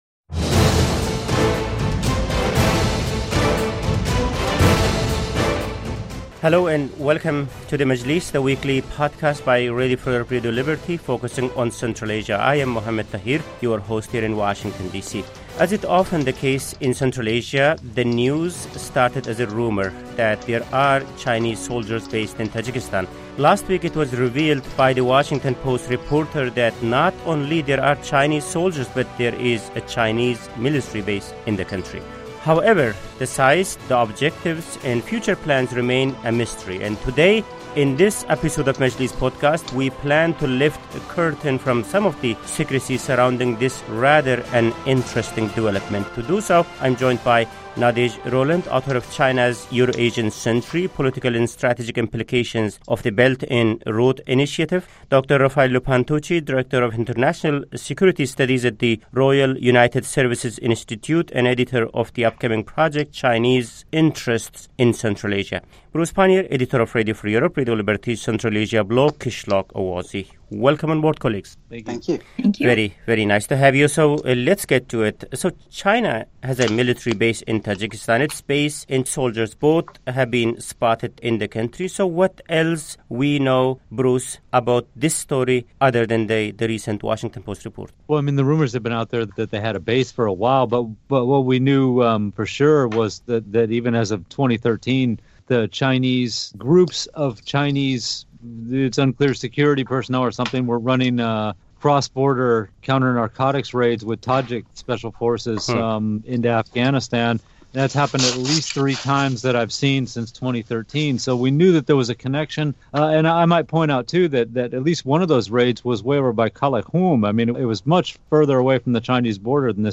On this week's Majlis Podcast, our panel discusses Beijing's security interests in Central Asia and how far they might go.